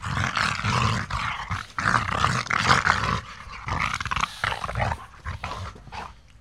gorilla-sound